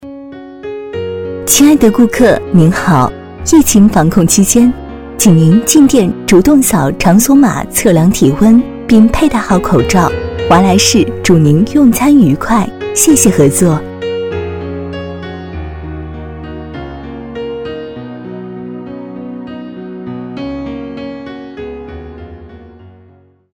B类女36|【女36号抒情播报】疫情防控
【女36号抒情播报】疫情防控.mp3